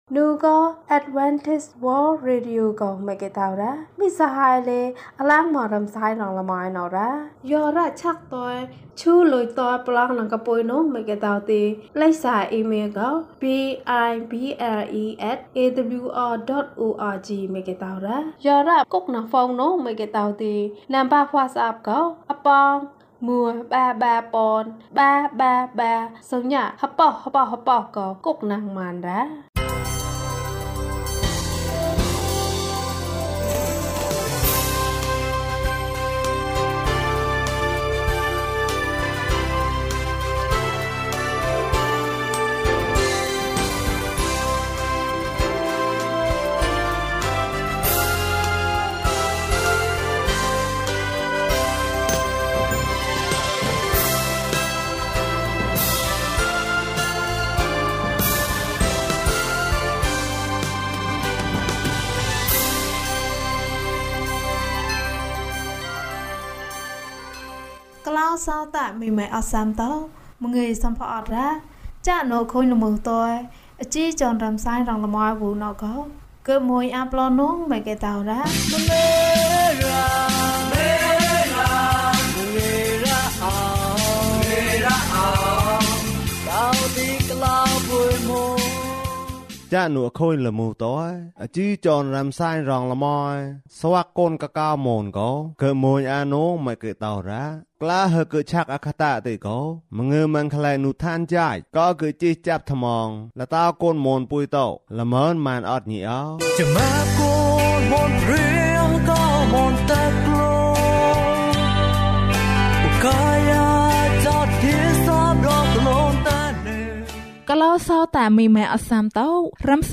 ဘုရားသခင်သည် ကျွန်ုပ်၏အရာအားလုံးဖြစ်သည်။၀၂ ကျန်းမာခြင်းအကြောင်းအရာ။ ဓမ္မသီချင်း။ တရားဒေသနာ။